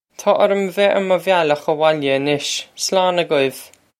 Taw orr-um veh air muh vyal-okh a-wohl-ya ah-nish. Slawn a-gwiv!
This is an approximate phonetic pronunciation of the phrase.